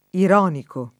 ironico [ ir 0 niko ] agg.; pl. m. ‑ci